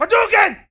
Category 😂 Memes
capcom fighting hadouken ken ryu street-fighter surge-fist sound effect free sound royalty free Memes